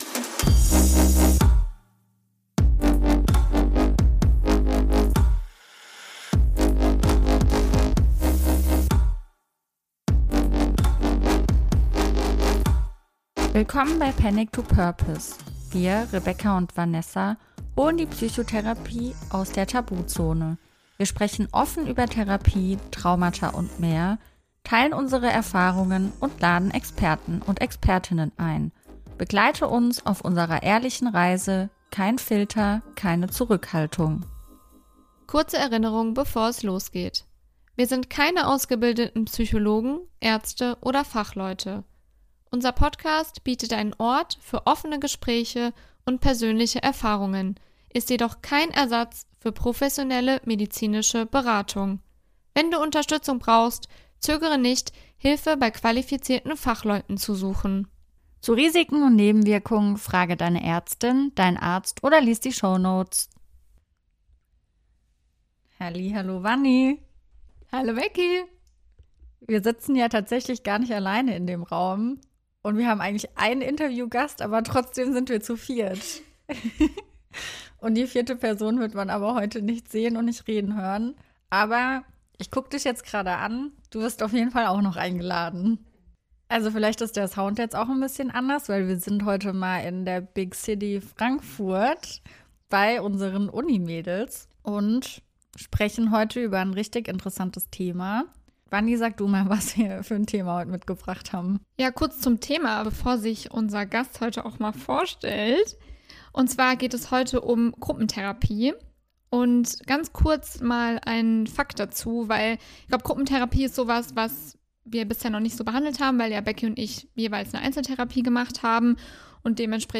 Ein Interview mit einer Patientin ~ Panic to Purpose - Dein Mental Health Podcast